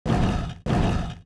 walk_1.wav